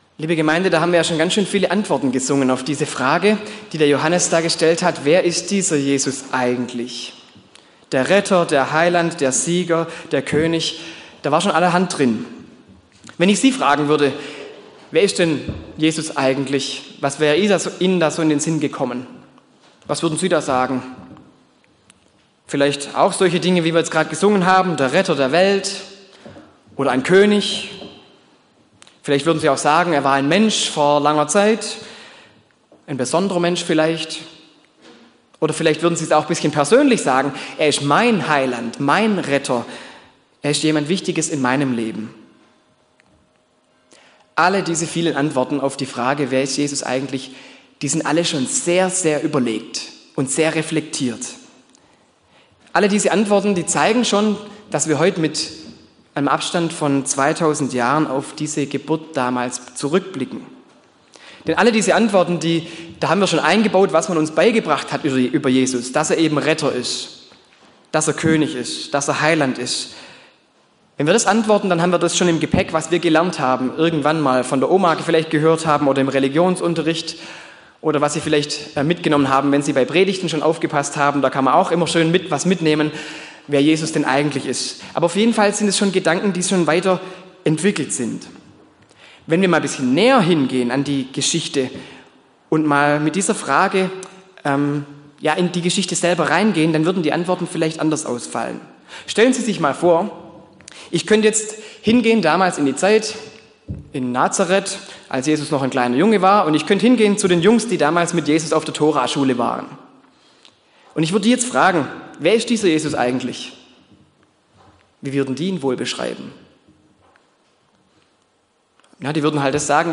Weihnachtsgottesdienst Die Predigt zum Nachlesen als PDF: Hebr 1 – Jesus das Wort Gottes